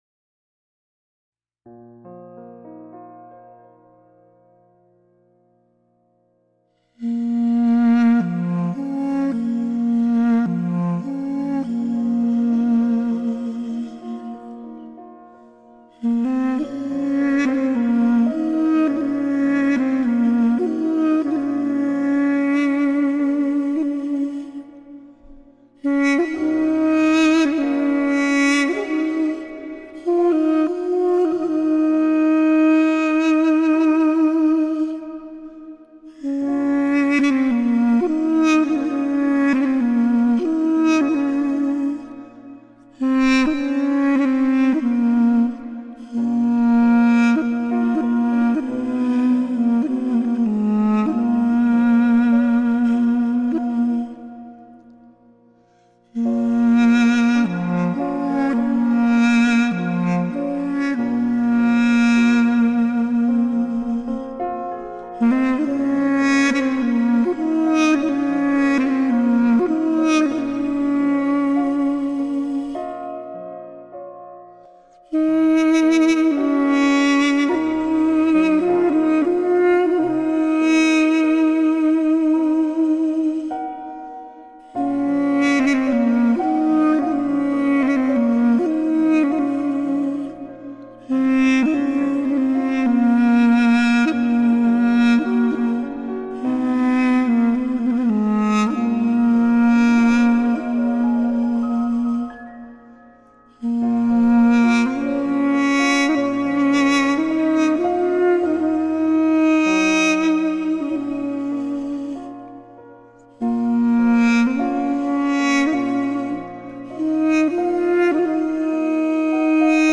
למרגוע הלב ולהתעוררות הרחמים ניגון הבעל שם טוב הקדוש